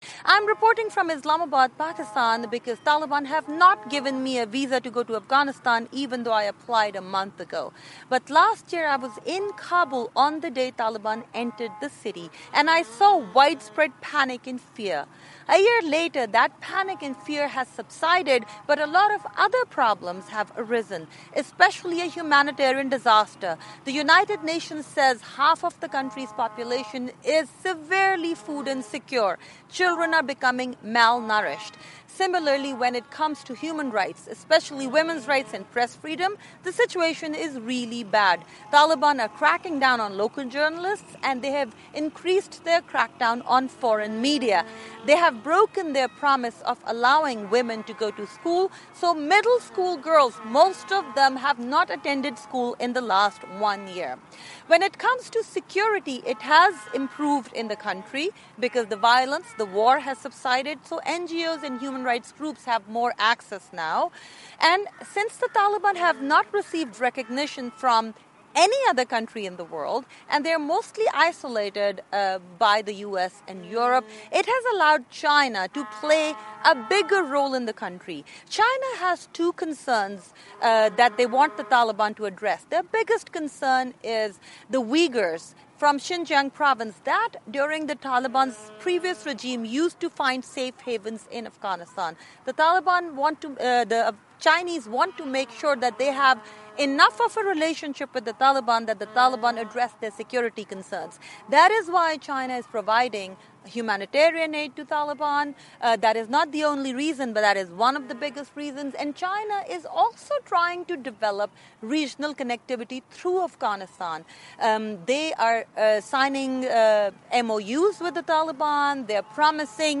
我从巴基斯坦伊斯兰堡发来报道，因为塔利班没有核发给我去阿富汗的签证，尽管我一个月前就申请了。